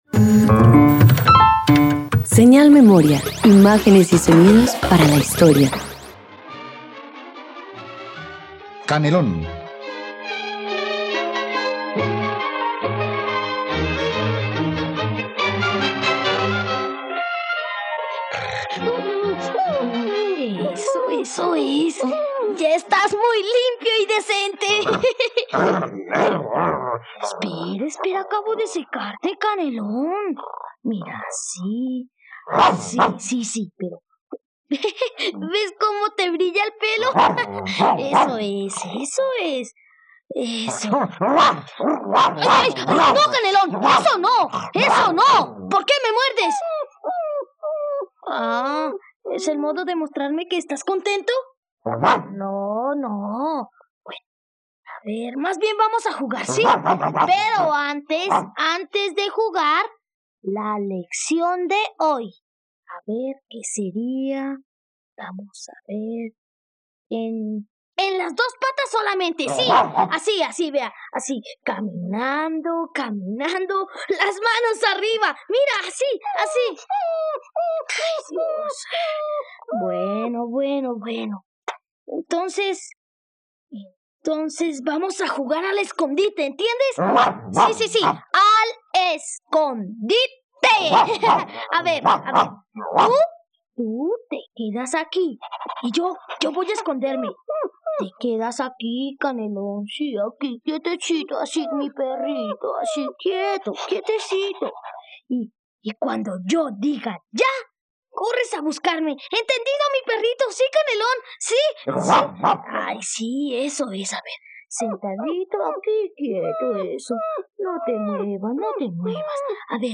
Canelón: radioteatro dominical
La Radiodifusora Nacional de Colombia transmitió en los años 70 un espacio enfocado a jóvenes preadolescentes llamado Radioteatro para muchachos, el cual tenía grandes diferencias con el espacio tradicional de radioteatro de los domingos.